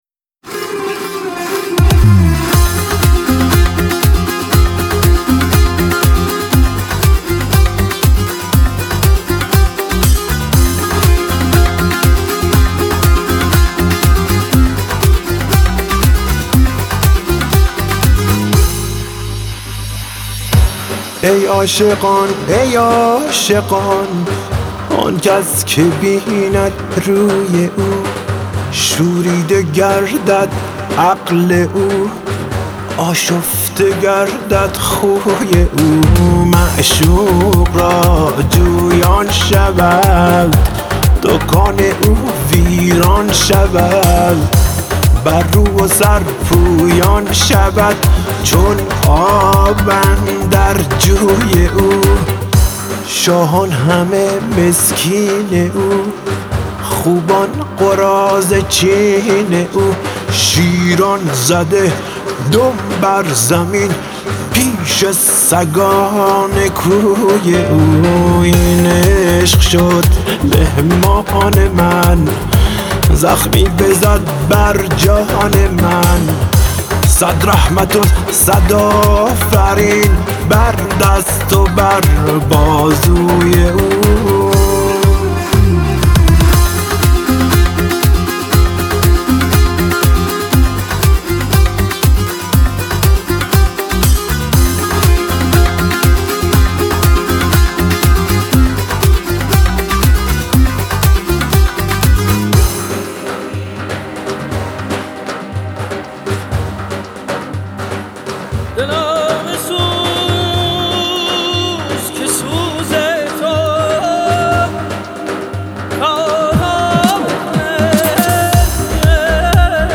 این نسخه بیس بالاتری نسبت به ورژن آلبوم دارد